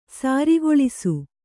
♪ sārigoḷisu